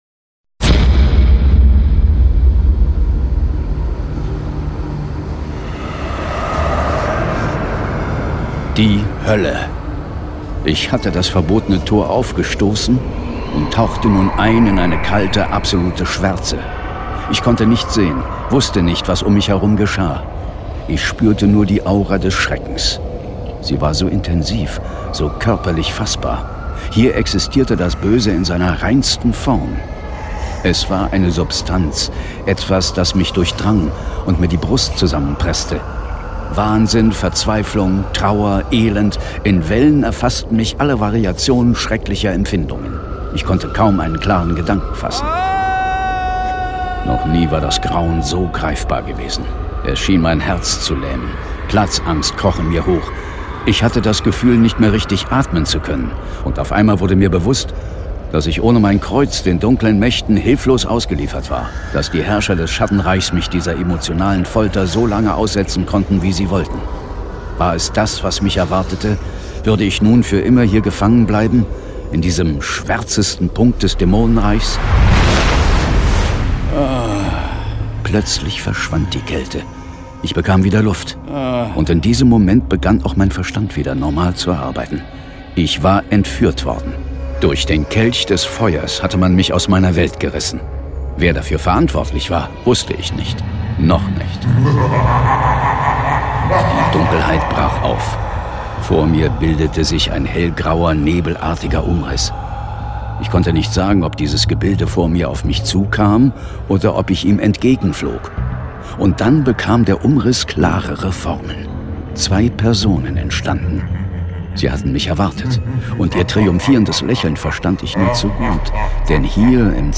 John Sinclair - Folge 61 Im Zentrum des Schreckens (II/III). Hörspiel.